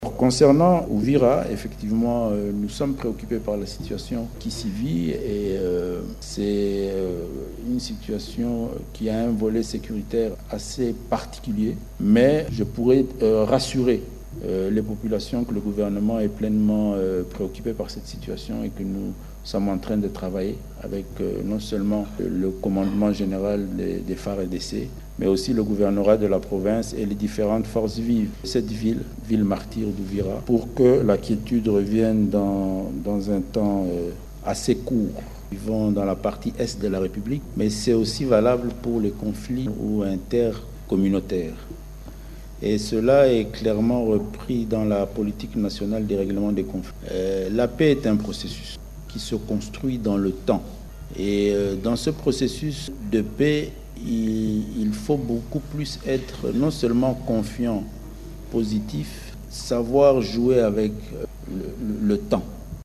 Il répondait à une question lors du briefing de presse coanimé avec son collègue en charge de la Communication et des Médias, Patrick Muyaya, à Kinshasa.
Propos de Jacquemain Shabani :